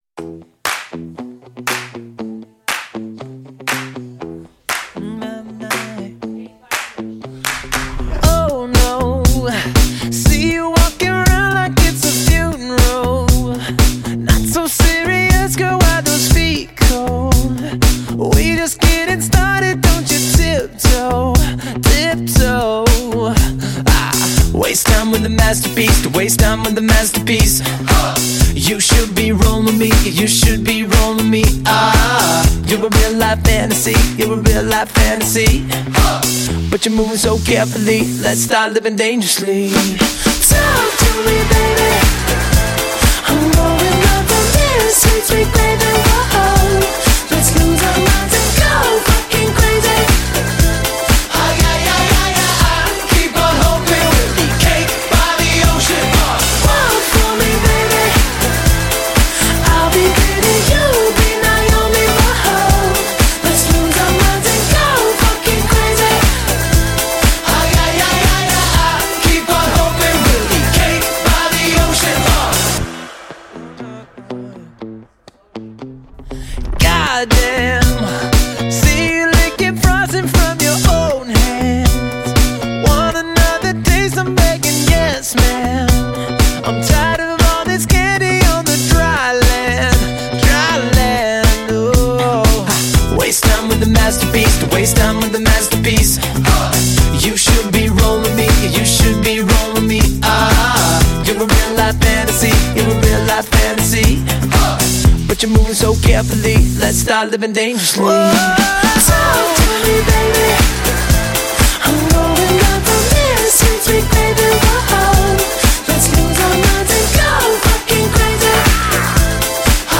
Pop 2010er